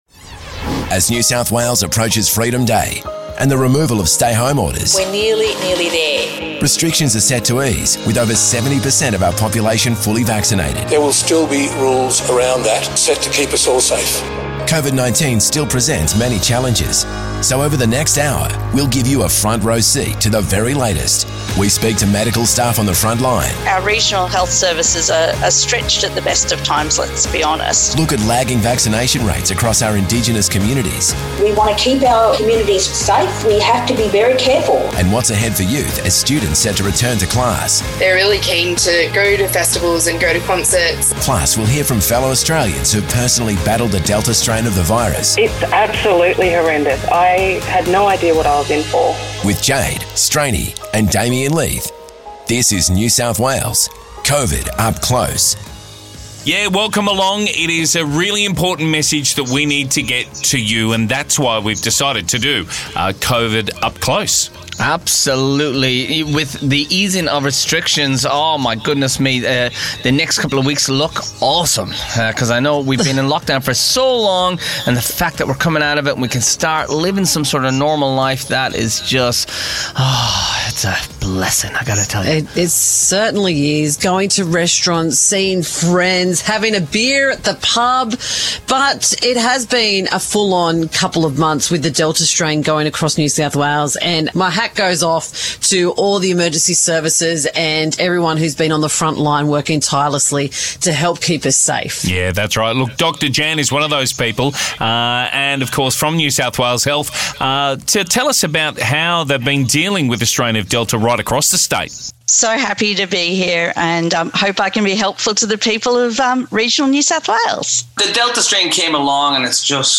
This COVID Up Close Special gives you a front-row seat into how our hospital staff are dealing with patients in ICU, plus we talk to patients who've recently battled the delta strain of the virus. You'll also hear about challenges ahead for our Indigenous communities lagging behind on vaccination rates, and insights into what's coming for NSW youth as students prepare to return to class October 25.